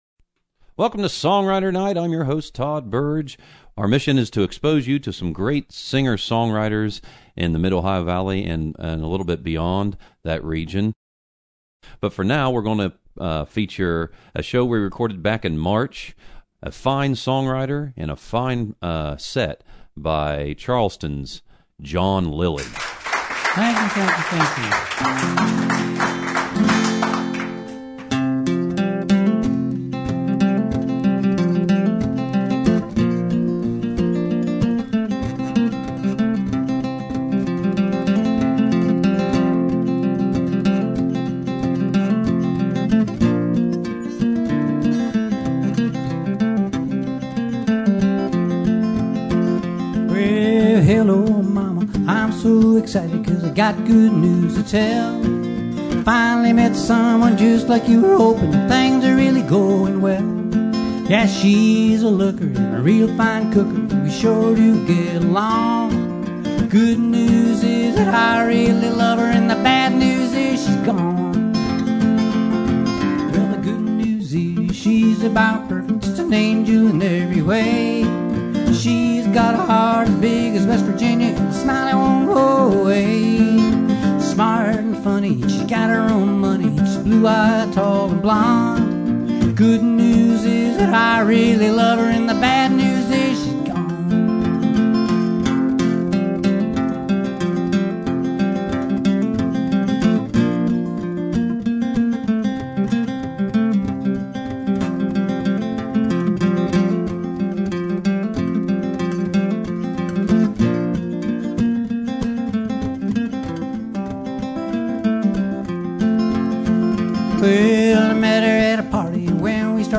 stereo 26:45